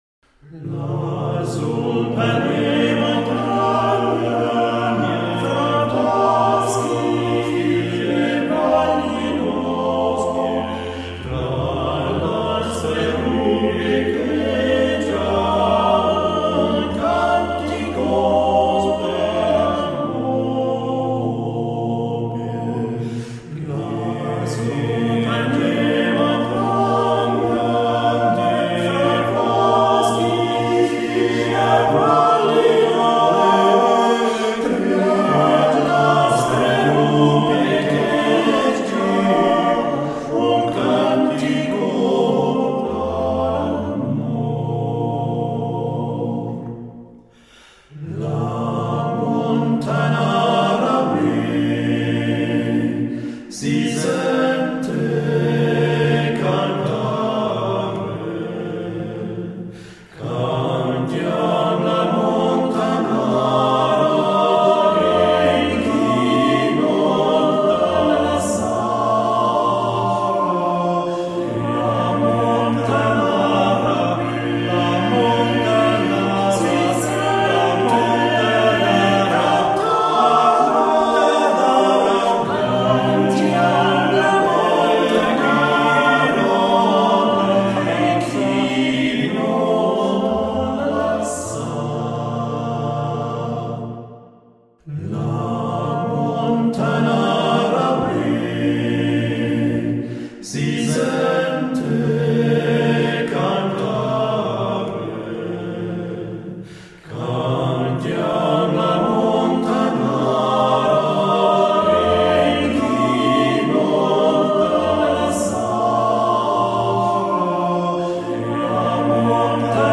Volksmusik